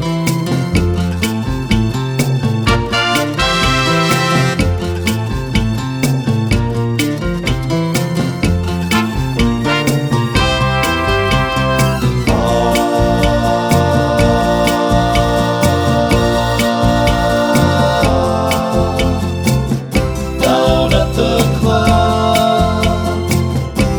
no Backing Vocals Soul / Motown 2:56 Buy £1.50